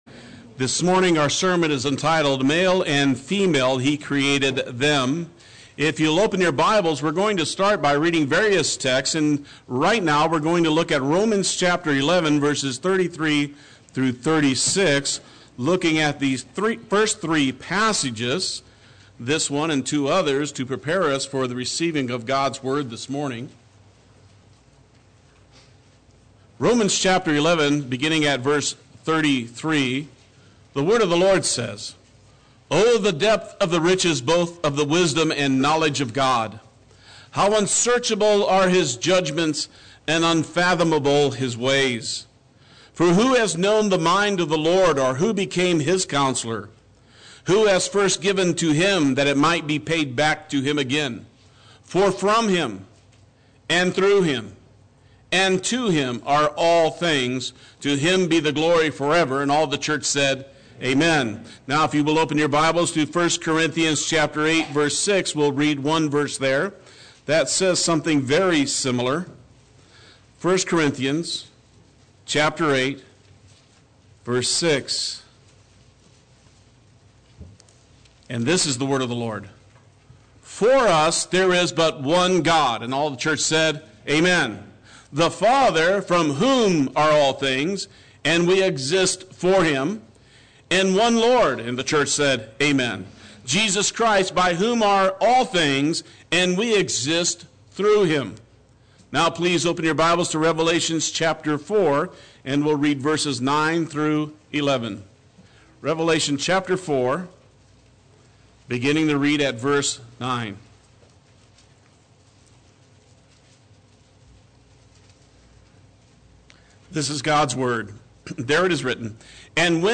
Play Sermon Get HCF Teaching Automatically.
He Created Them” Sunday Worship